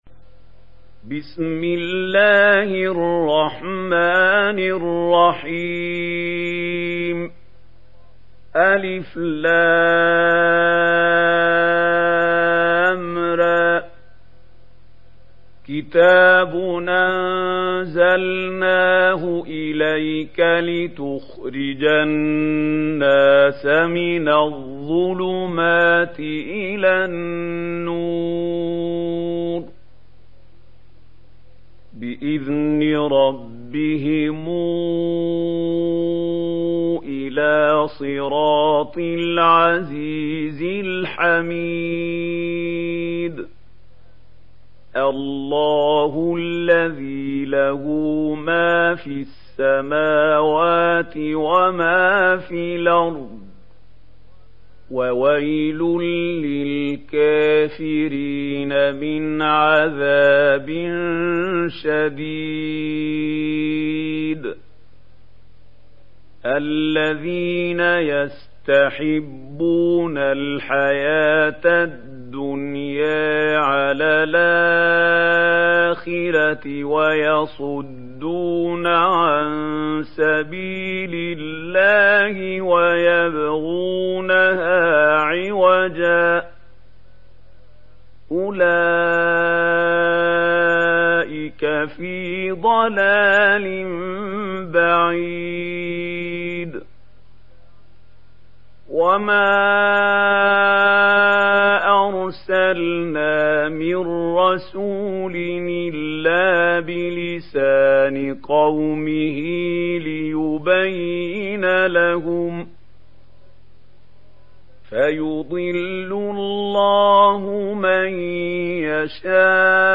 Surat Ibrahim mp3 Download Mahmoud Khalil Al Hussary (Riwayat Warsh)